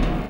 0202 DR.LOOP.wav